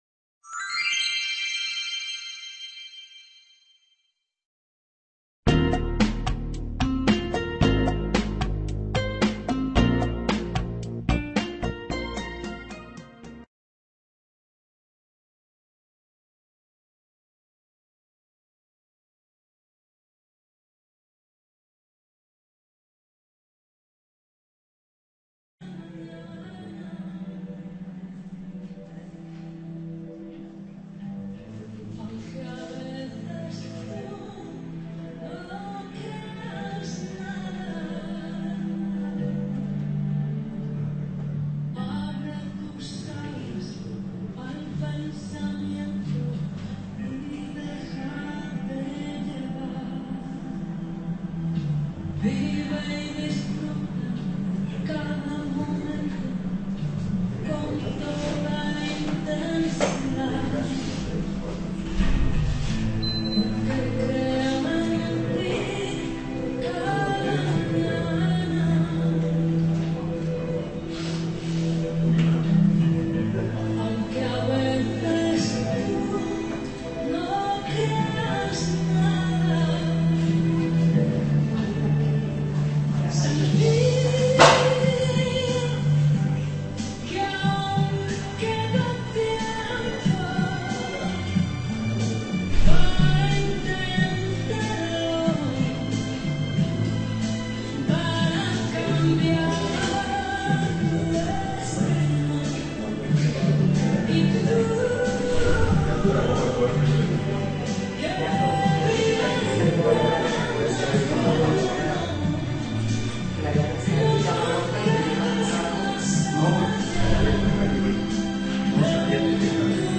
imparte esta conferencia